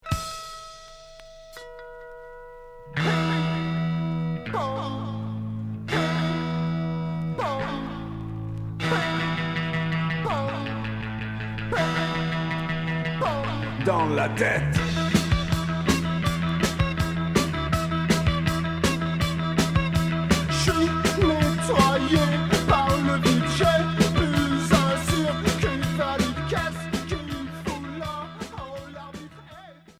Cold wave Unique 45t